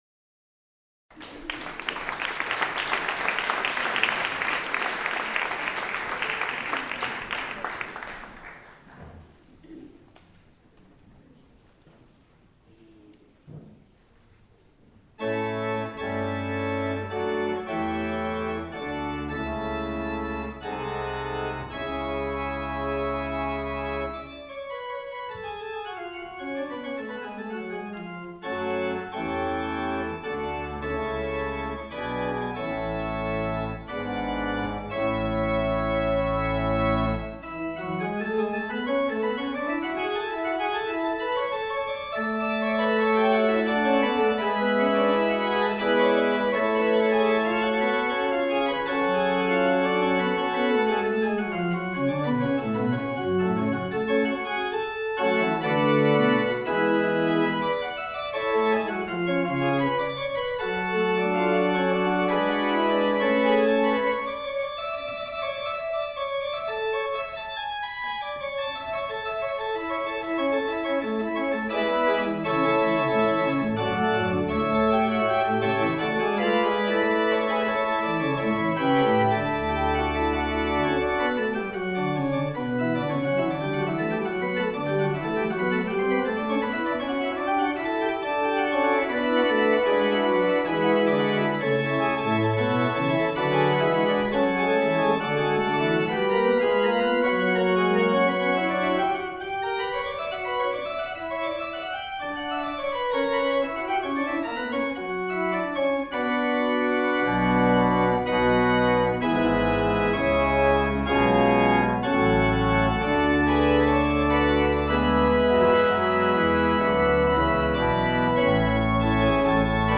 Concert de l’Avent – organ works
organsection.mp3